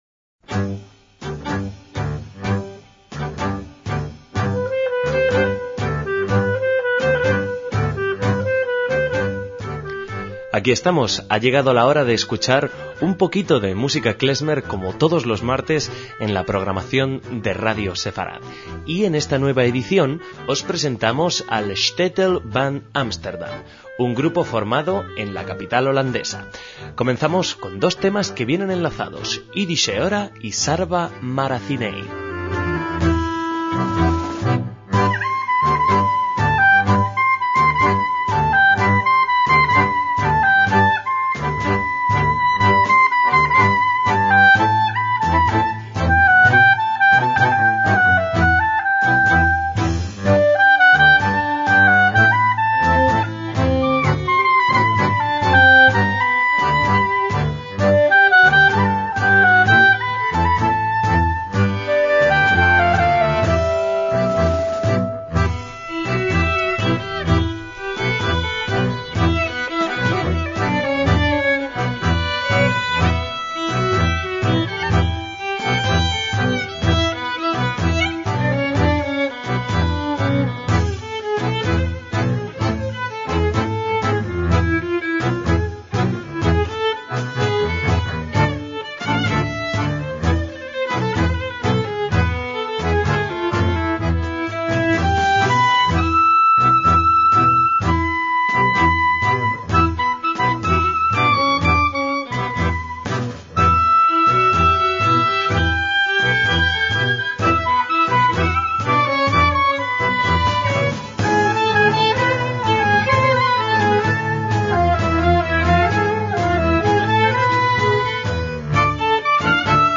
MÚSICA KLEZMER
contrabajista